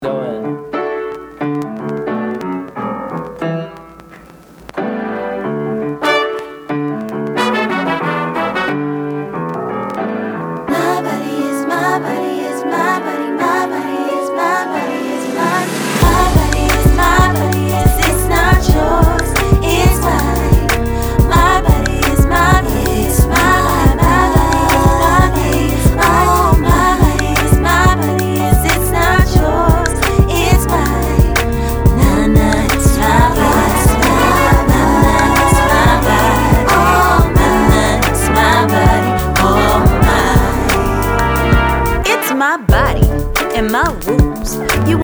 Hip Hop, soul and electronic